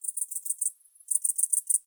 sounds_grasshopper.ogg